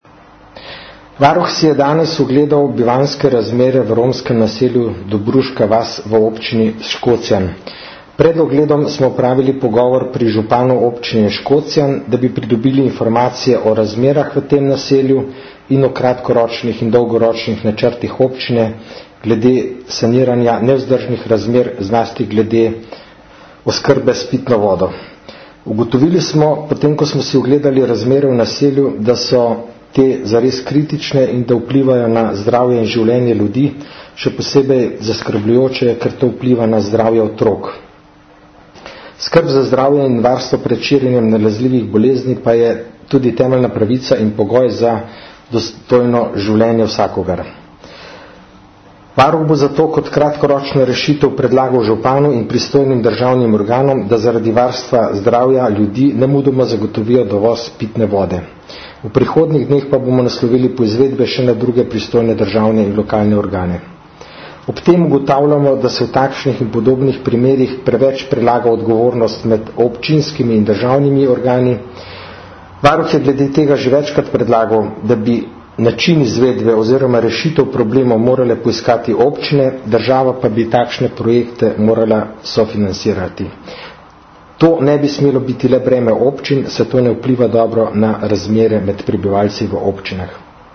Zvočni posnetek izjave (MP3) - govori namestnik varuhinje Jernej Rovšek